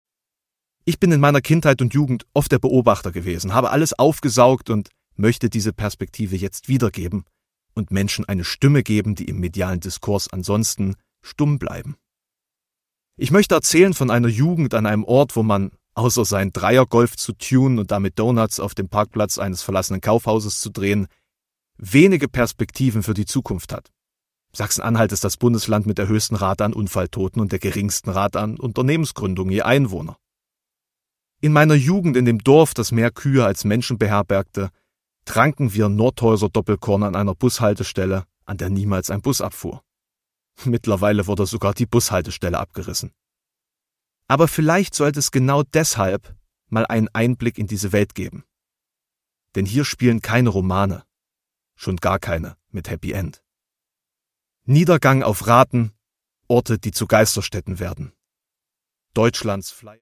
Produkttyp: Hörbuch-Download
Gelesen von: Alexander Prinz
Das Hörbuch wird gelesen vom Autor.